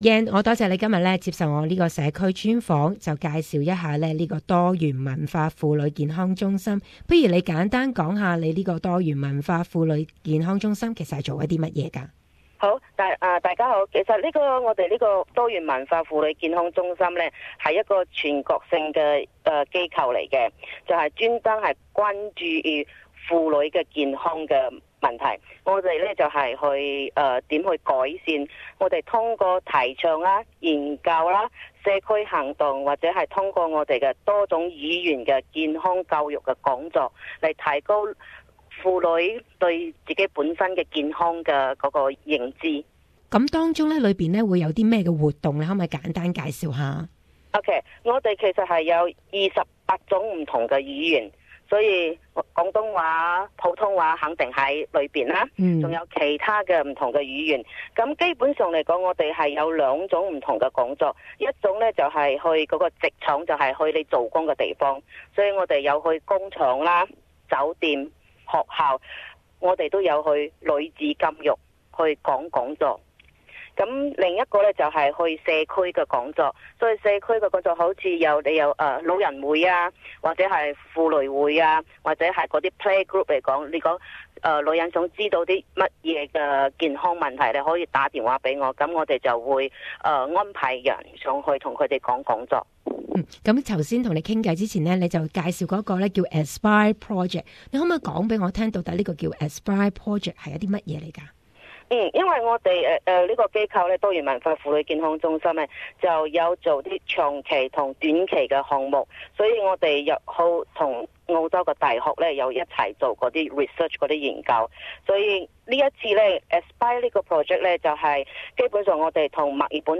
Community Interview